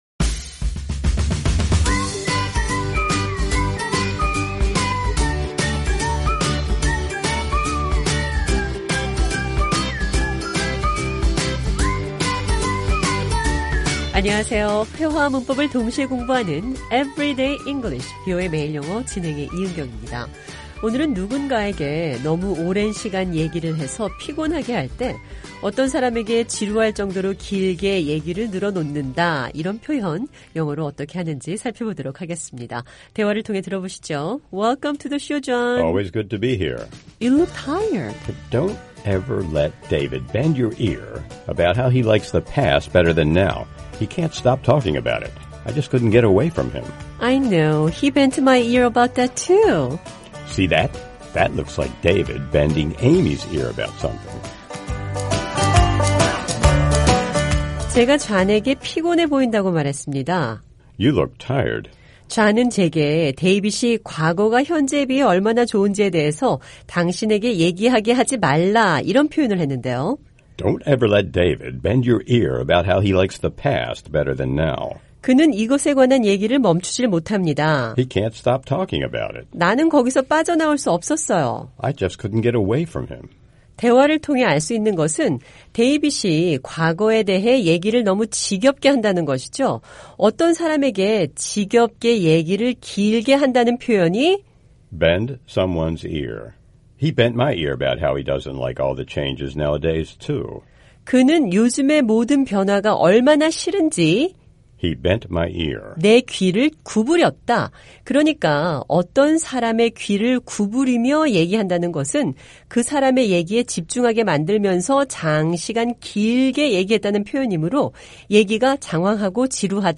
오늘은 누군가에게 너무 오랜시간 얘기를 해서 피곤하게 할 때, 어떤 사람에게 지루할 정도로 길게 얘기를 늘어 놓는다는 표현 영어로 어떻게 하는지 살펴보겠습니다. 대화를 통해 들어보시죠